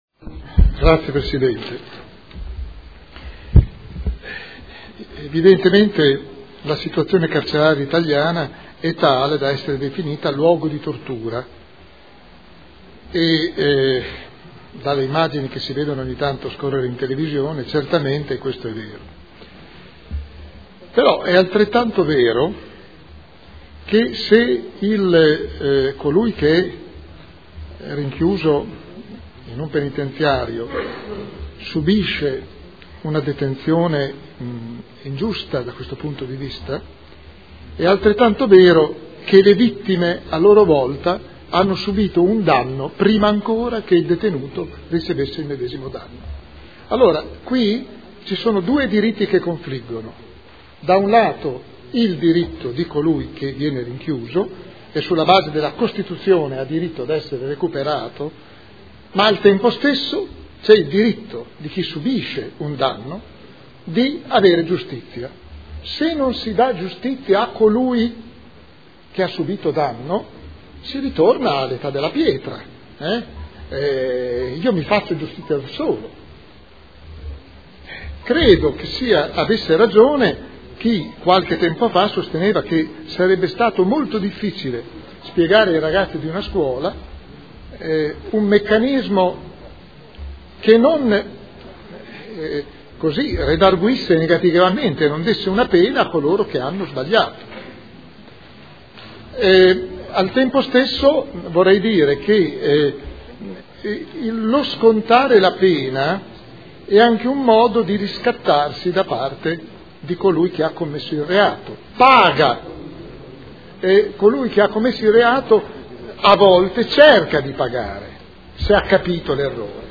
Seduta del 24/10/2013. Dibattito sugli ordini del giorno e sull'emendamento presentati dai consiglieri Barcaiuolo, Ricci, Cavani e Trande